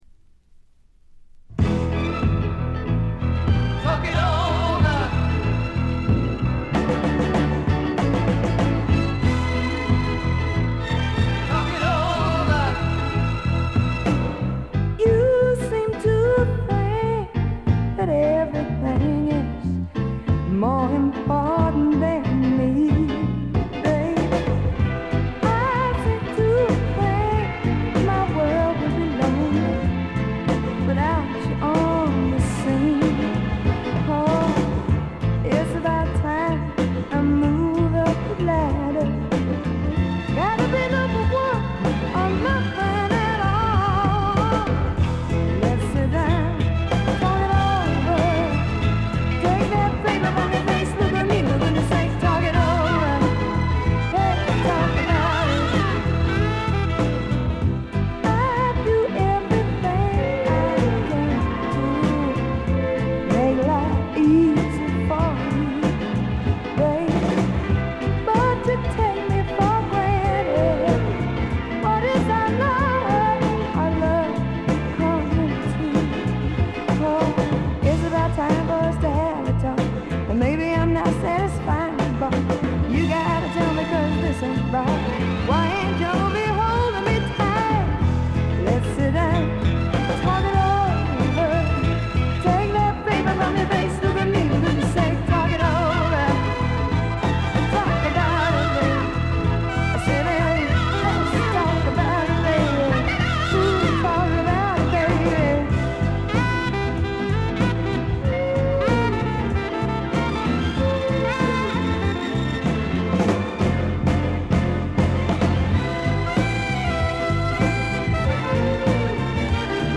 B面ラストのフェードアウトの消え際に軽い周回ノイズ。
まさしく豪華絢爛なフィリー・サウンドででこれも傑作です。
試聴曲は現品からの取り込み音源です。
Recorded at Sigma Sound Studios, Philadelphia, Pennsylvania.